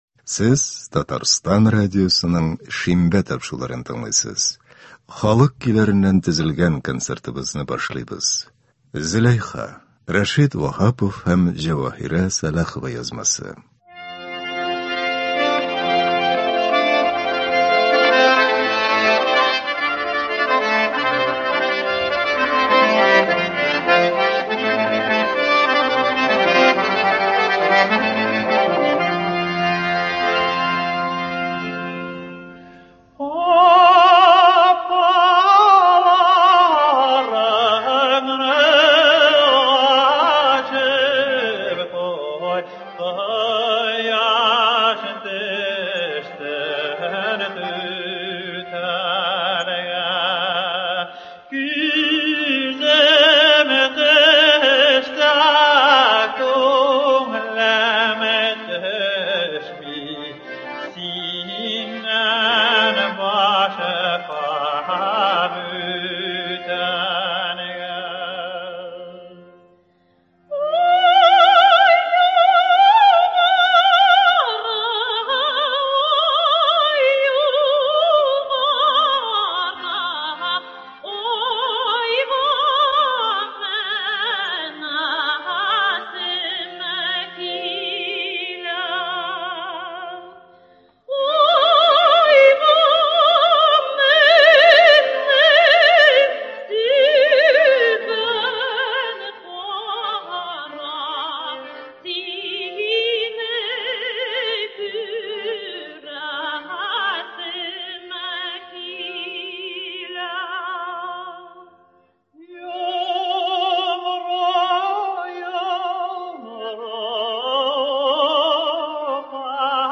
Татар халык җырлары (25.05.24)
Бүген без сезнең игътибарга радио фондында сакланган җырлардан төзелгән концерт тыңларга тәкъдим итәбез.